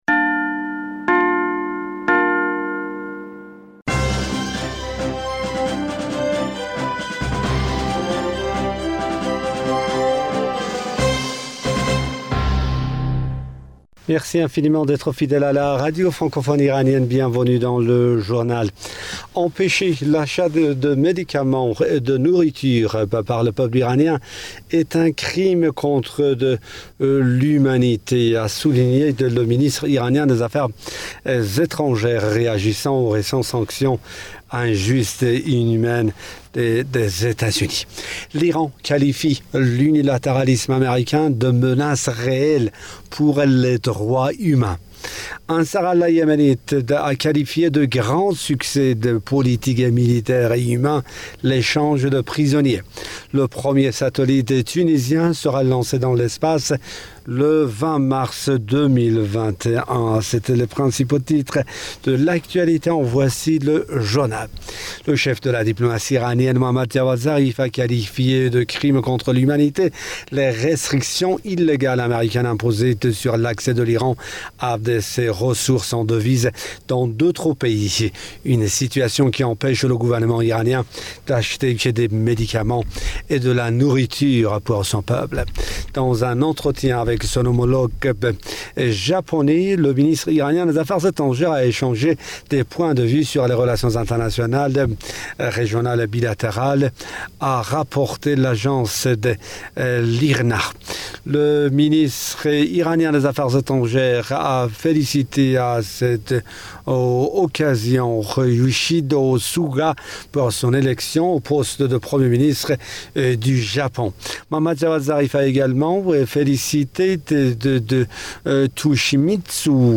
Bulletin d'information du 16 Octobre 2020